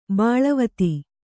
♪ bāḷavati